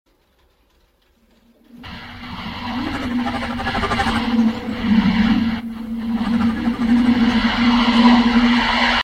• ELECTRONIC PULSES.mp3
electronic_pulses_fuy.wav